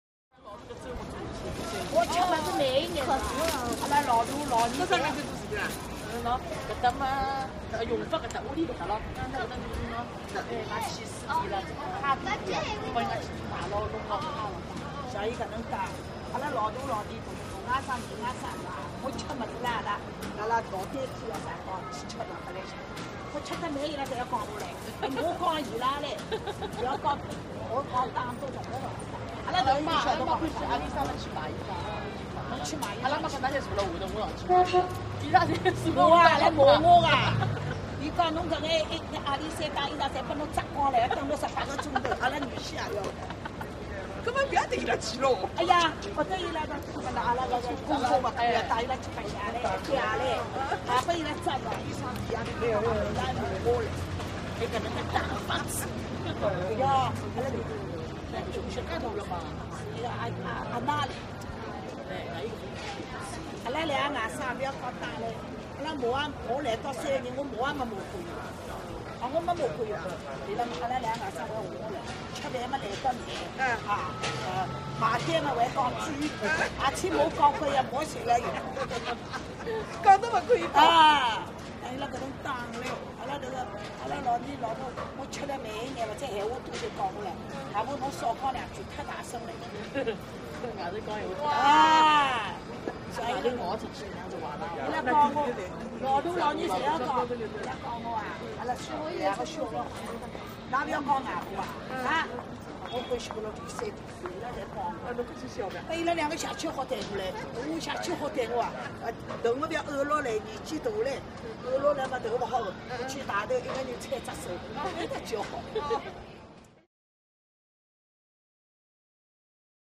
Chinatown Street, Three Women Close Walla With Busy Medium Perspective Movement, Walla, And Traffic With Sparse Honks.